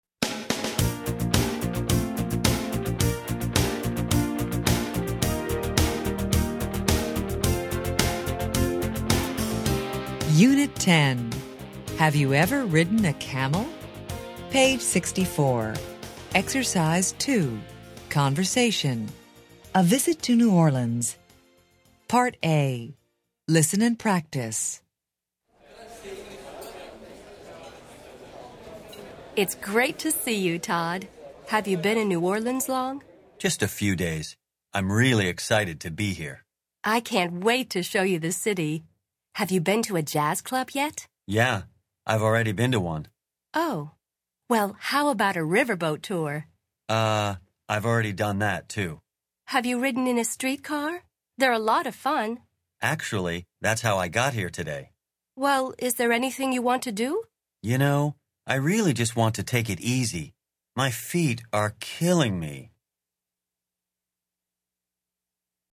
Interchange Third Edition Level 1 Unit 10 Ex 2 Conversation Track 29 Students Book Student Arcade Self Study Audio